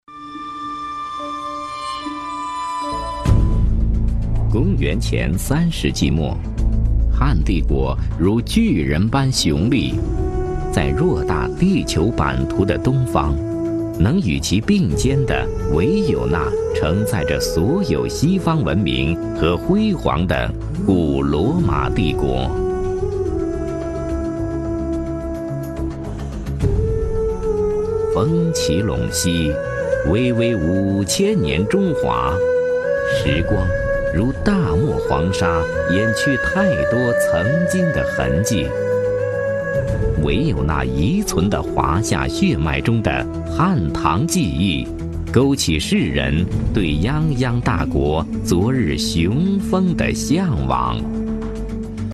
任志宏，男，出生于山西省太原市，演播艺术家，播音指导，主持人  。
自然科教纪录片配音
任志宏_纪录片_自然科教_汉武御酒业.mp3